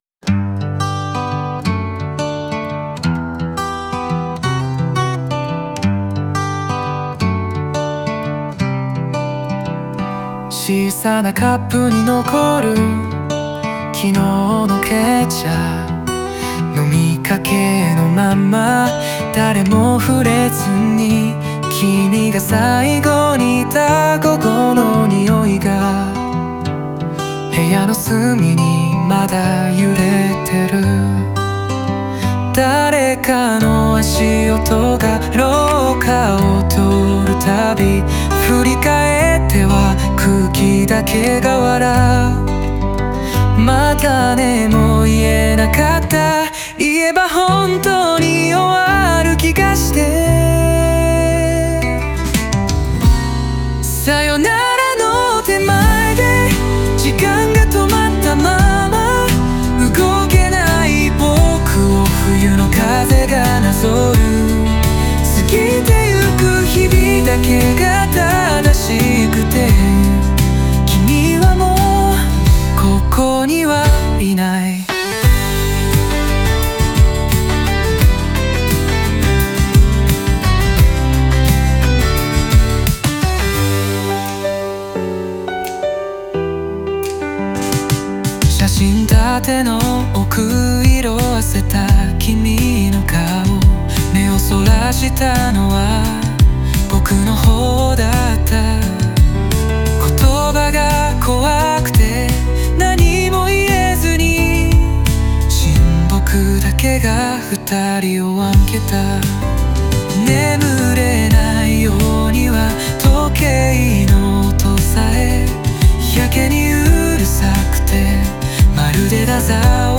オリジナル曲♪
時間が止まったままの空間で、喪失の痛みと向き合う姿が、寂しさと余韻を伴って響くバラードです。